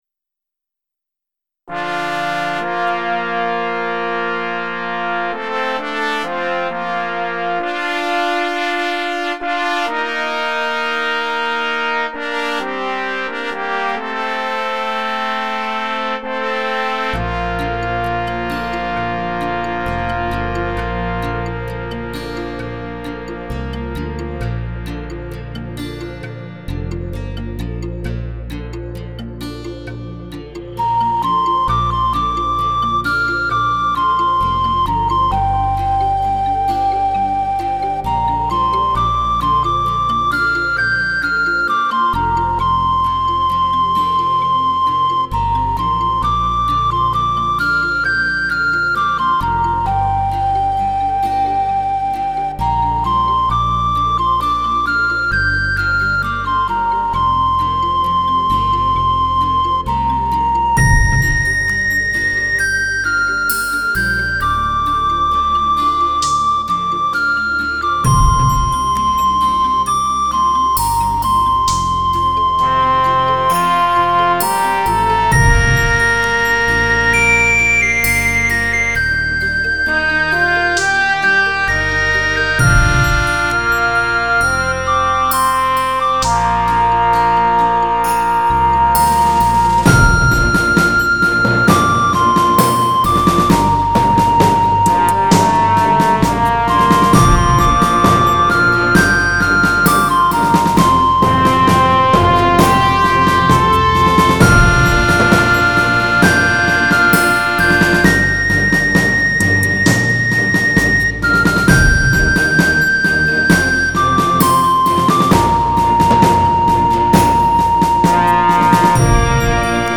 2周前 纯音乐 8